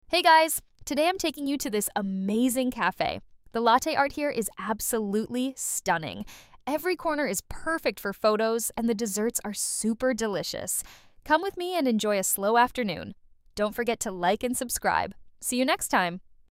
In this tutorial, we generate a social-style voiceover with ElevenLabs Turbo 2.5 and share the full setup plus optimization tips.
• Youthful energy: brisk pacing, positive mood.
speed1.05Slightly faster pacing for short-video rhythm.
Naturalness⭐⭐⭐⭐⭐Smooth phrasing, low synthetic artifacts.
Energy⭐⭐⭐⭐⭐Upbeat tempo and positive emotional tone.
Clarity⭐⭐⭐⭐Clear enough for mobile-first playback.